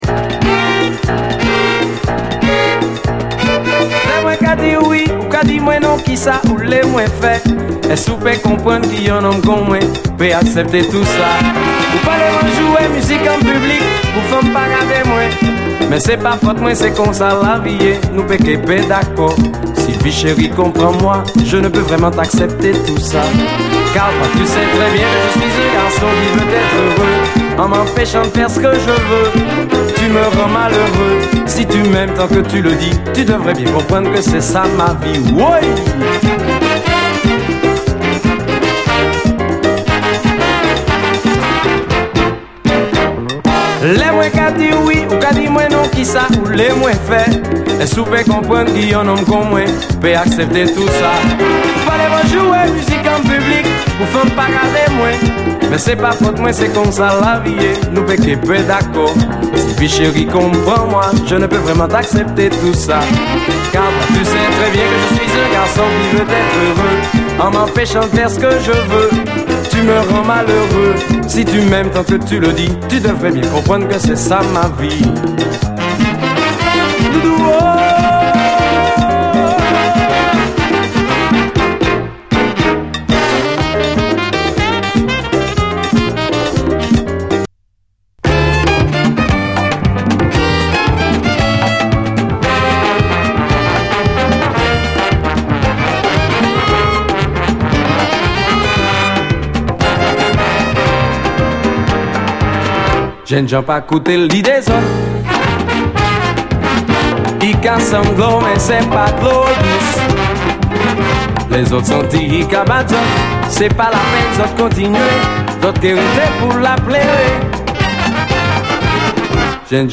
CARIBBEAN
ハッピーに弾むカリビアン・ダンサー